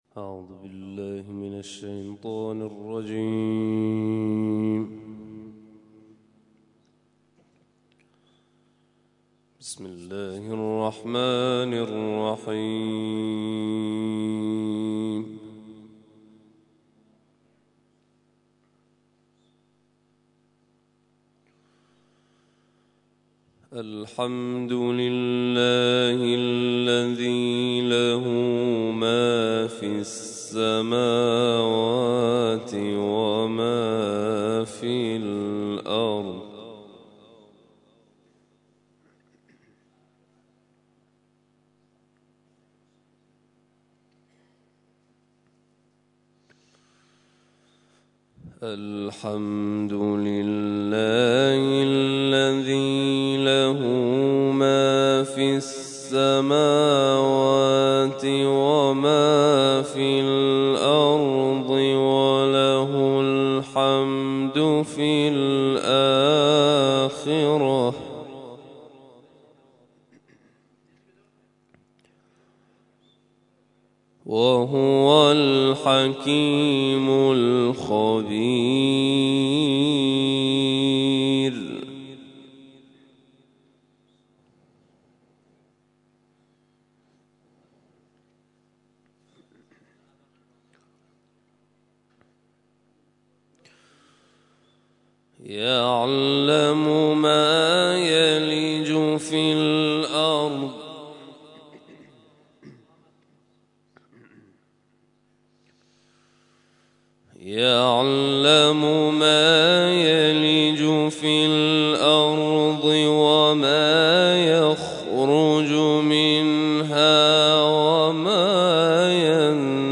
تلاوت صبح